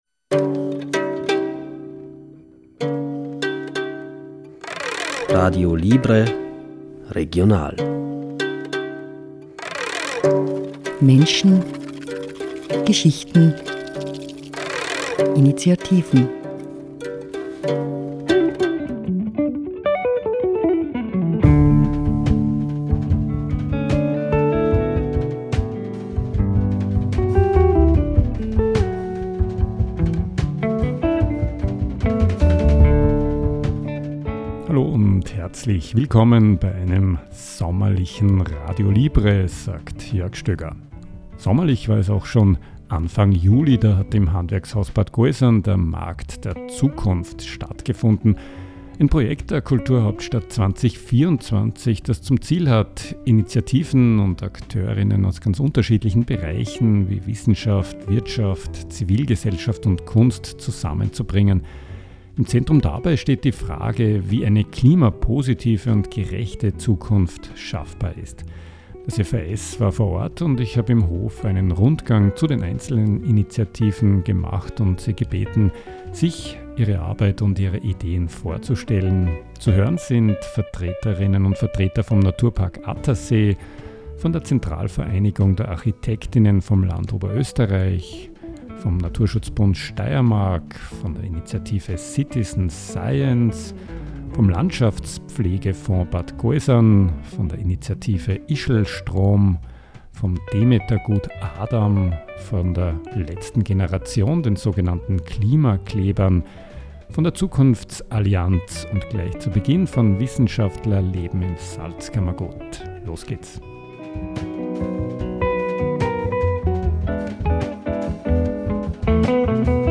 Akustischer Rundgang am Markt der Zukunft